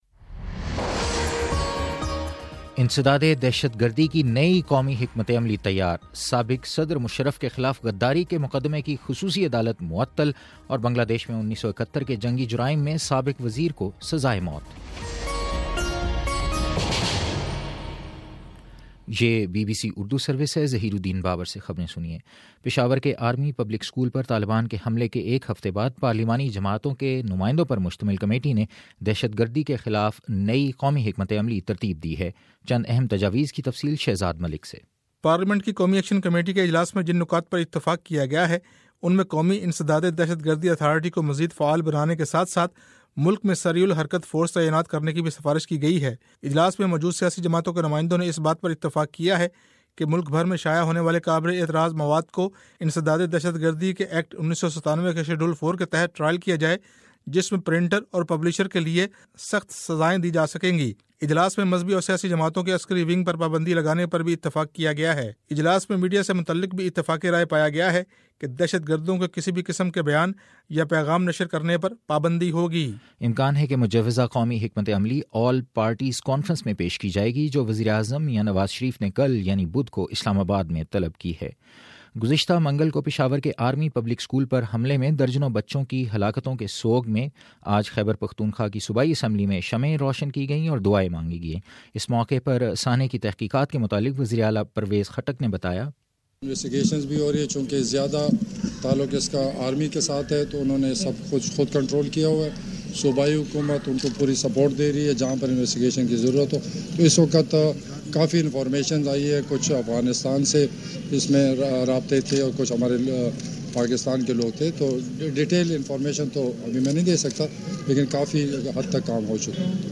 دسمبر23: شام سات بجے کا نیوز بُلیٹن